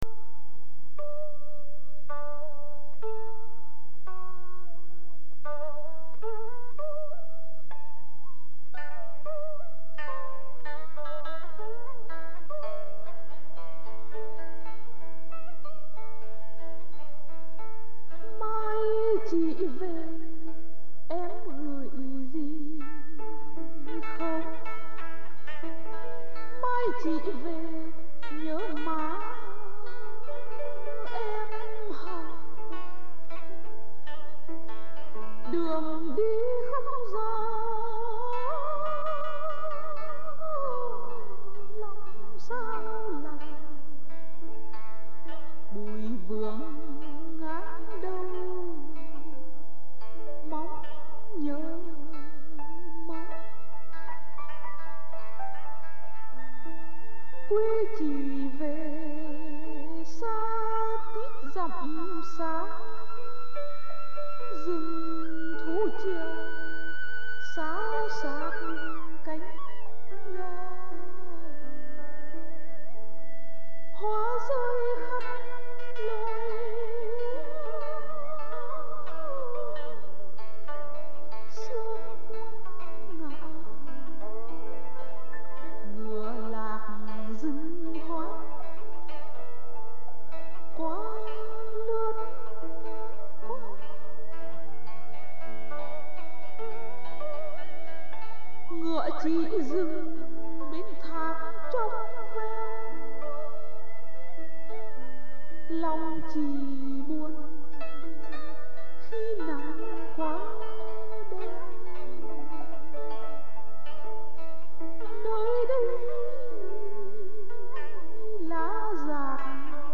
Cái chữ "gió" ngân nga mà nghe như tiếng gió, giọng ngâm quá hay và quá sang. không có nức nở và luôn buồn như ít nhiều ngâm sĩ khác.